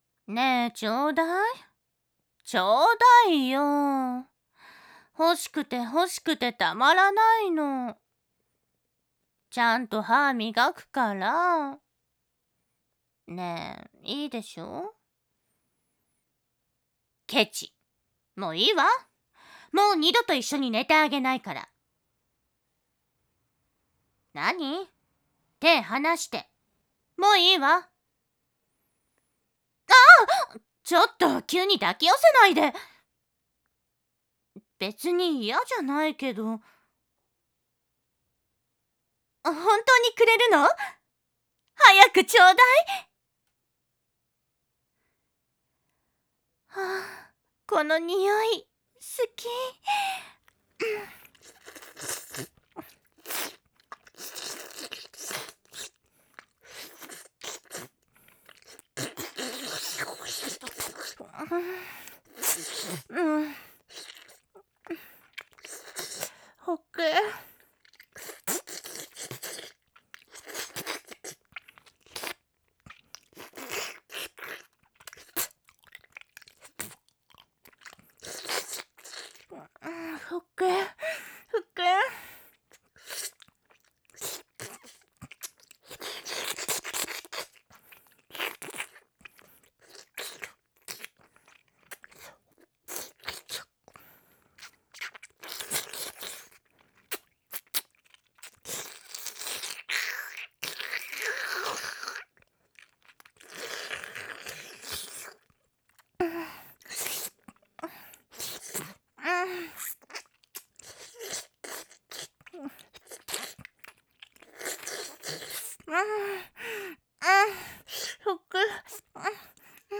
#2 ただ、ミルク棒をぺろぺろ お姉様系
お姉さん系.wav